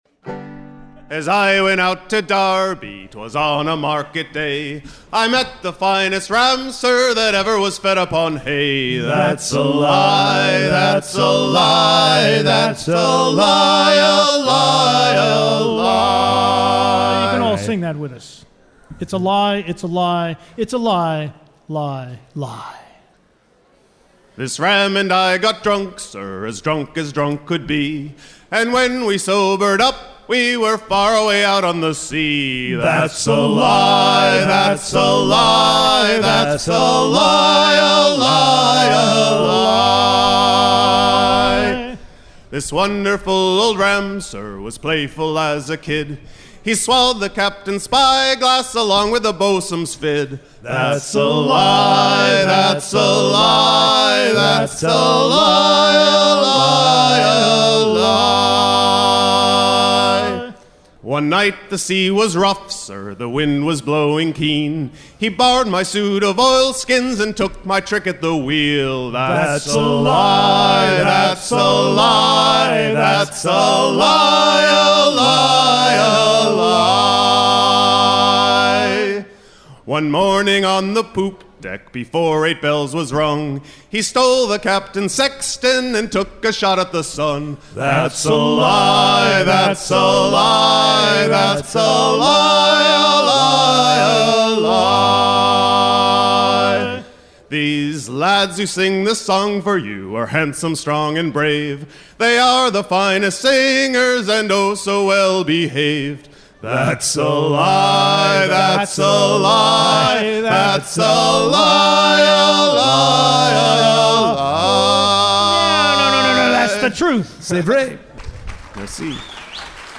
en concert
Pièce musicale éditée